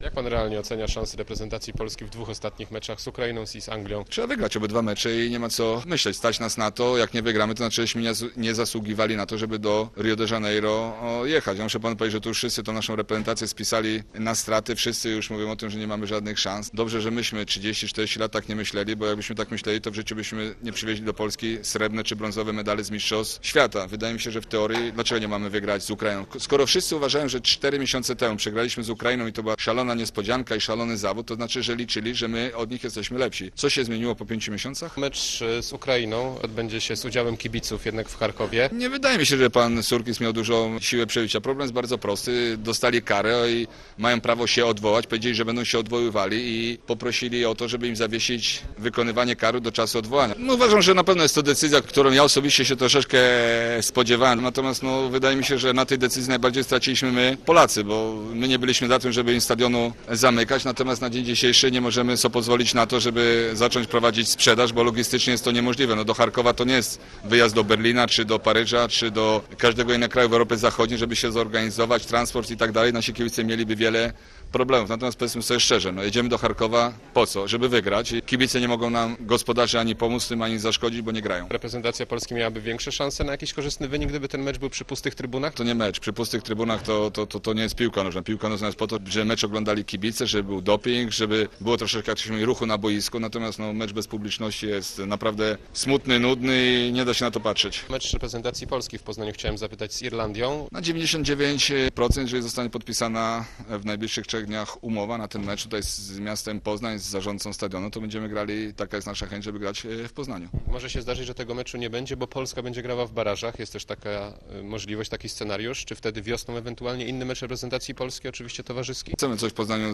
4ru1gdxg9fx7y8o_boniek_rozmowa.mp3